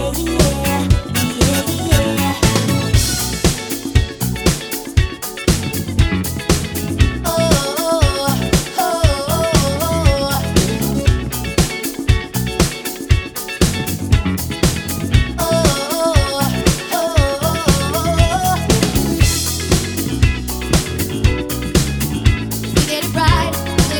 no Backing Vocals Pop (2010s) 4:47 Buy £1.50